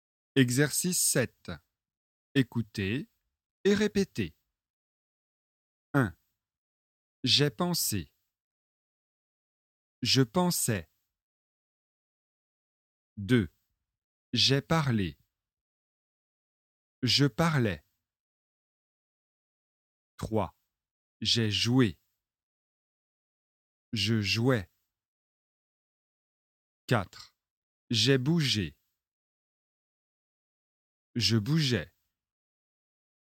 Exercice 7 : écoutez et répétez